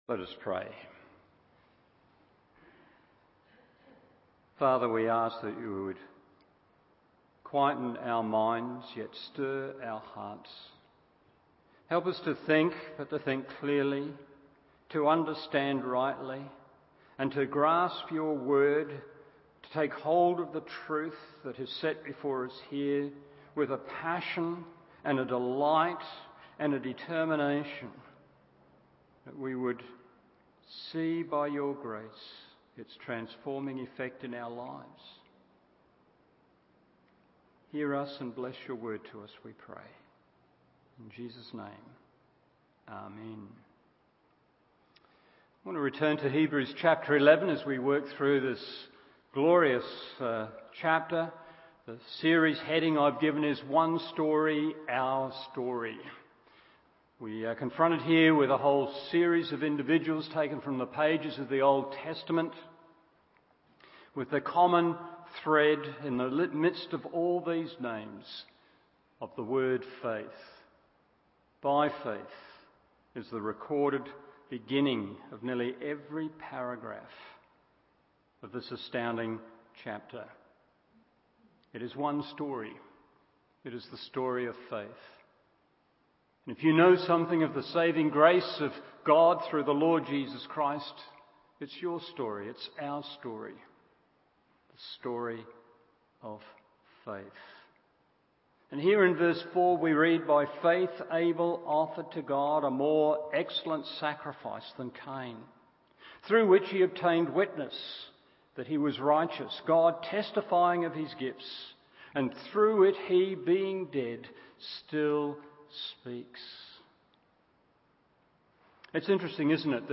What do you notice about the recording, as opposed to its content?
Evening Service Hebrews 11:4 1. Faith manifests itself in worship 2. Worship without faith is worthless 3. Faith has a Living Voice…